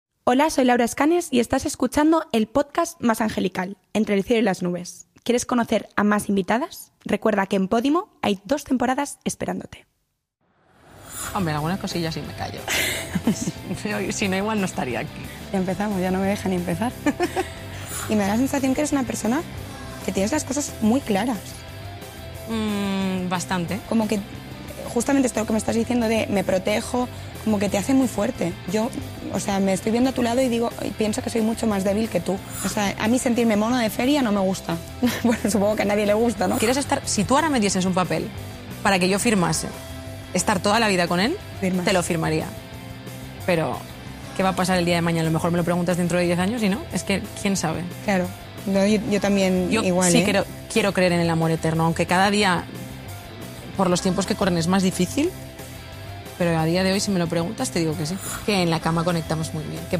Play Rate Listened List Bookmark Get this podcast via API From The Podcast 2 Un podcast donde Laura Escanes se refugia en un espacio seguro para encontrarse con sus amigos y con gente de la que aprenderemos cosas importantes.
(Pre-grabado en la calle).